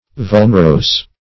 Vulnerose \Vul"ner*ose`\